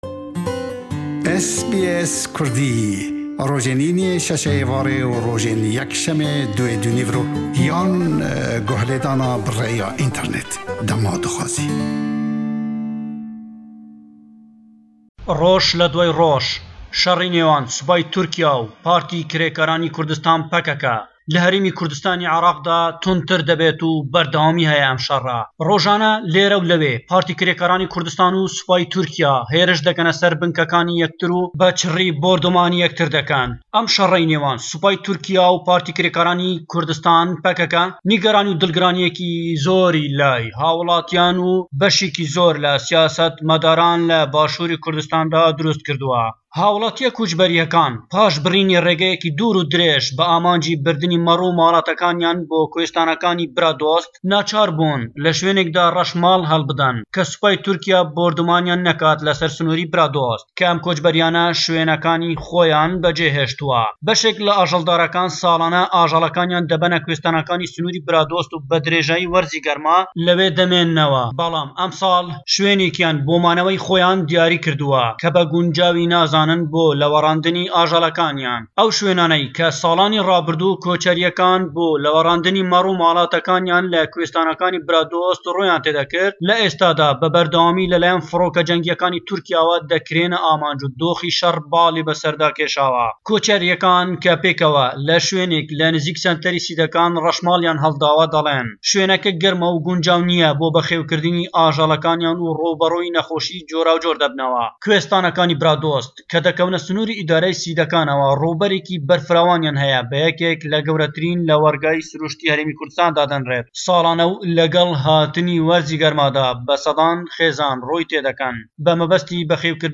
Our correspondent reports from the Kurdish Capital Hewler on the latest crisis between Turkey and the PKK on Qandil mountain where the PKK has established their headquarters and military base.